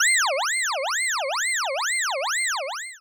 Touhou-SFX - A collection of Touhou-like and 2hu relevant audio that I've collected as I went about dev-ing games.
ufo.wav